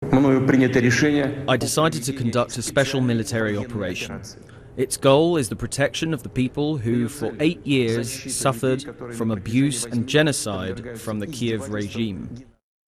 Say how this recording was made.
The Russian president made the following announcement in a TV address.